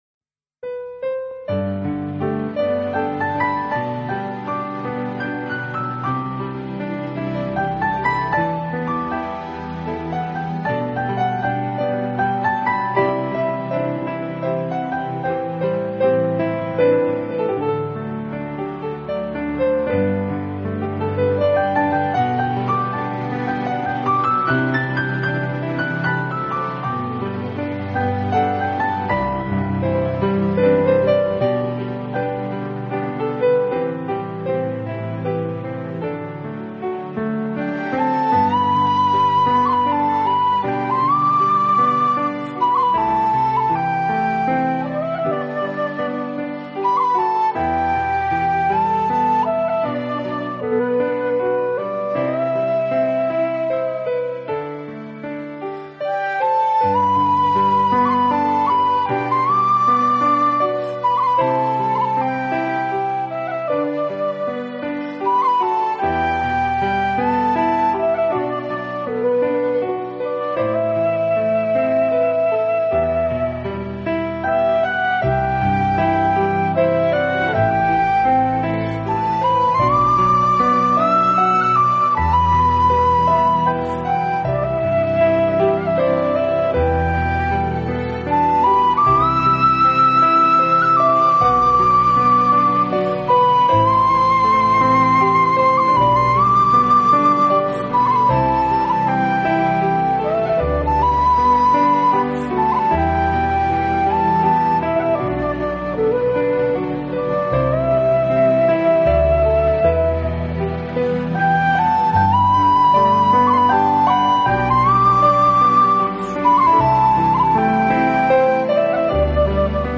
从自然而来的气息沁人心脾 采撷的大自然音符滋润心灵
2. 主要强调一种轻柔的绝对性，是最纯净、最能安定人心的音乐处方笺 。
3. 独特超广角音场、空灵缥缈的编曲构成最具高临场感的大自然音乐。